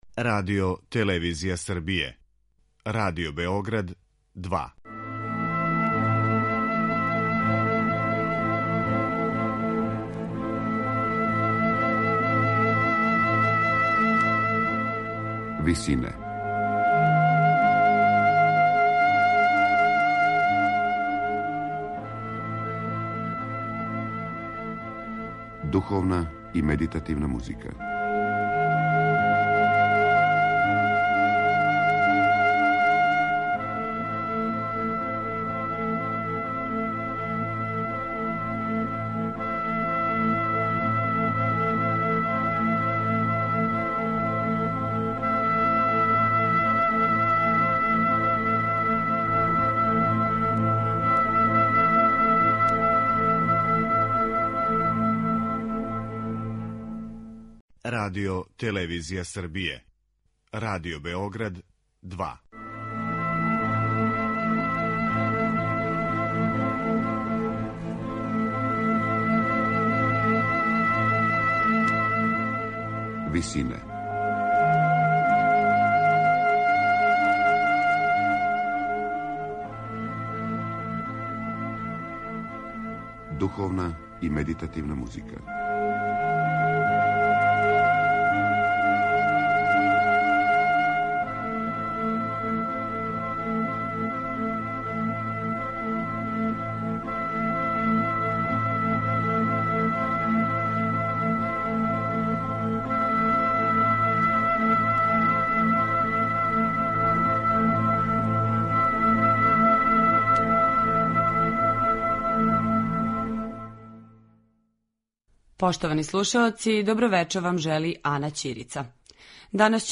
ансамбл за рану музику